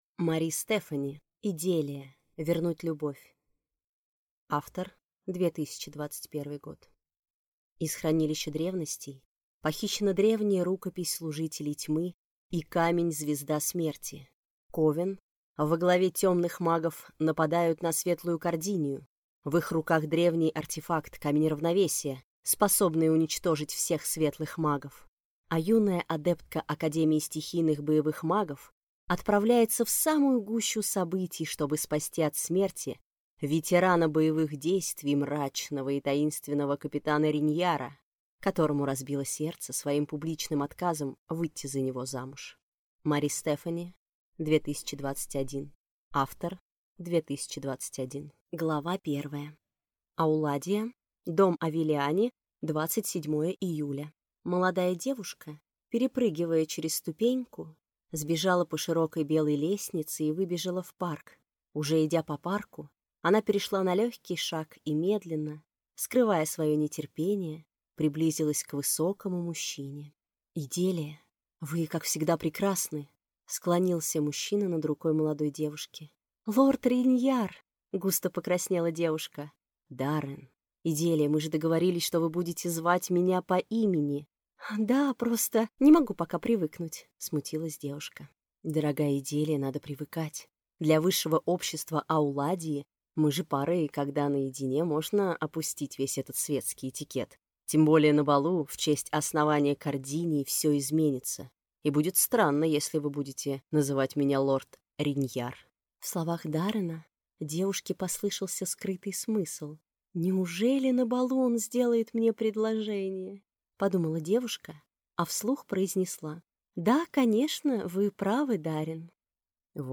Аудиокнига Иделия. Вернуть любовь | Библиотека аудиокниг